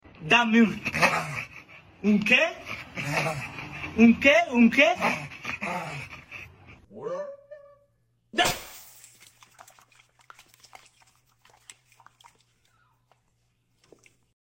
Cute Dog Sound Effects Free Download